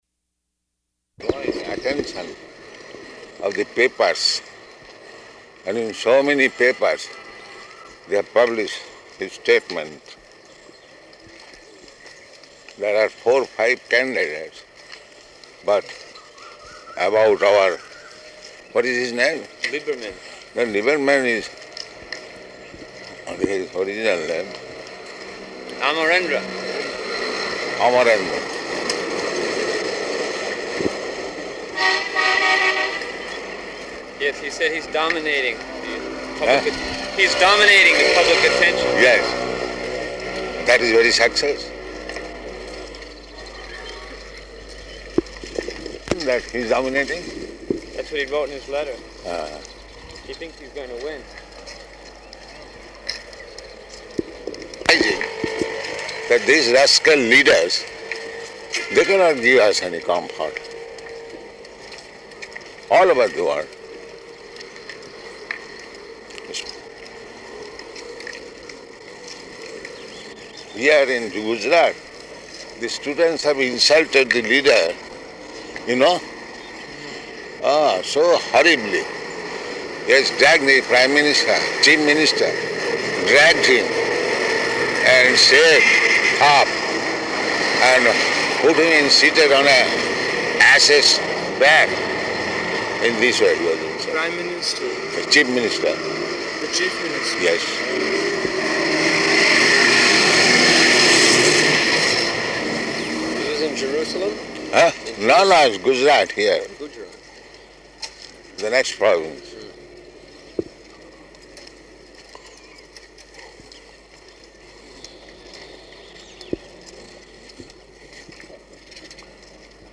Morning Walk --:-- --:-- Type: Walk Dated: April 24th 1974 Location: Hyderabad Audio file: 740424MW.HYD.mp3 Prabhupāda: ...drawing the attention of the papers.